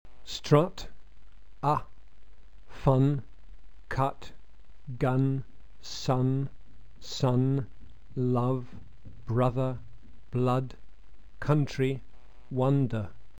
Lax vowels are always short